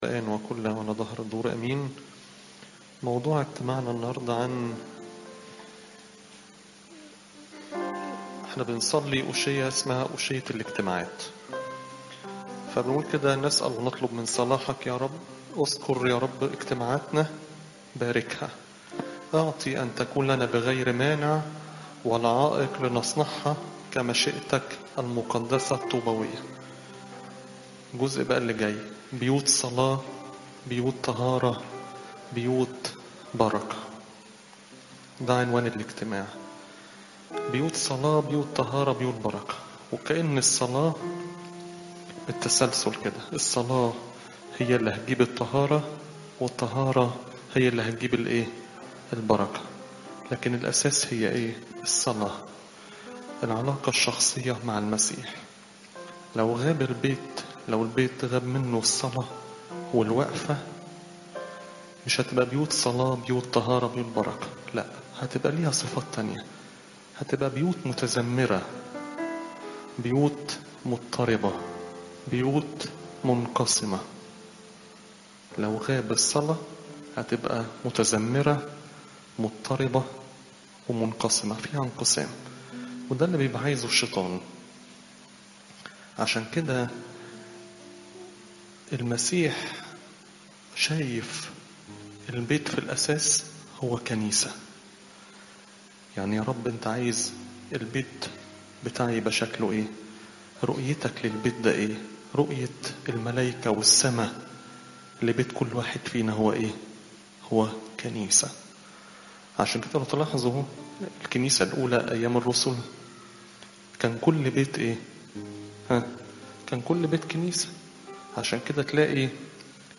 عظات الكنيسة - بطريركية الاقباط الارثوذكس - كنيسة السيدة العذراء مريم بالزيتون - الموقع الرسمي